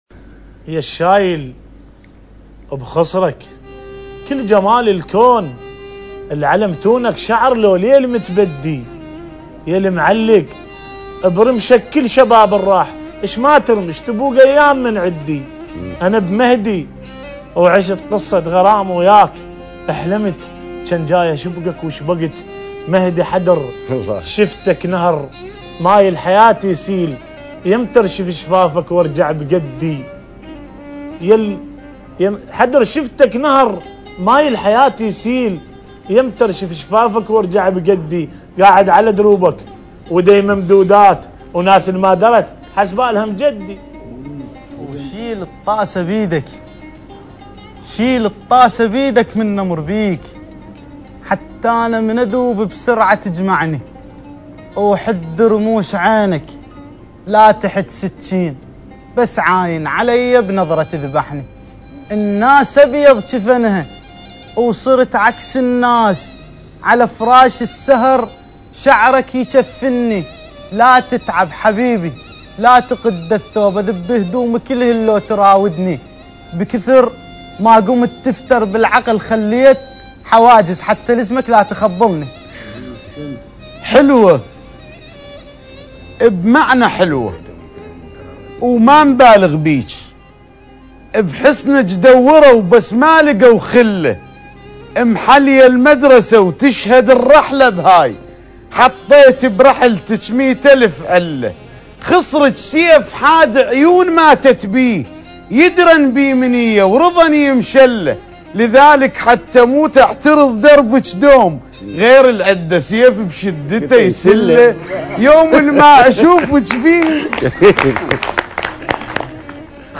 مناضره شعريه عراقيه خسران الي ميسمعلهه